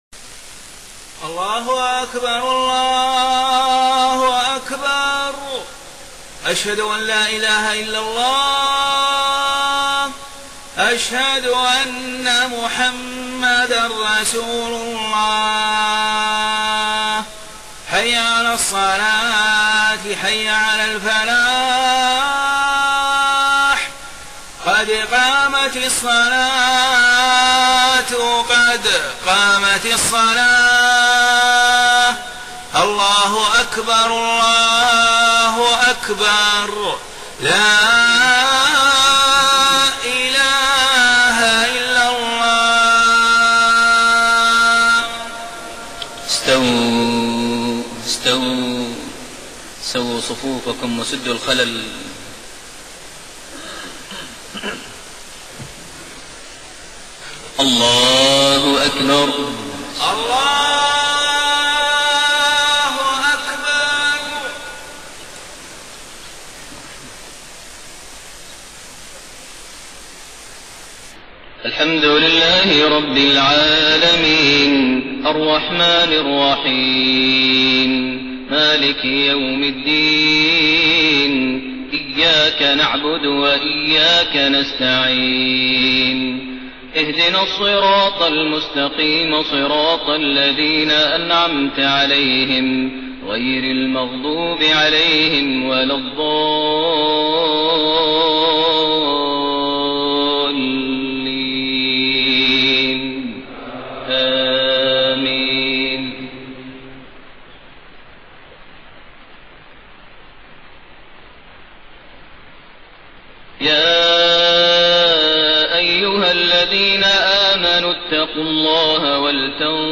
صلاة العشاء 18 ذو الحجة 1432هـ خواتيم سورتي الحشر 18-24 و المنافقون 9-11 > 1432 هـ > الفروض - تلاوات ماهر المعيقلي